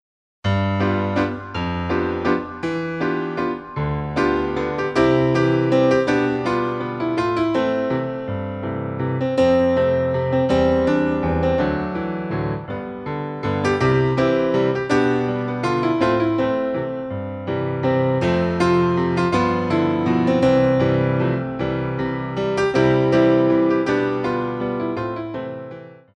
PIROUETTES EN DIAGONAL I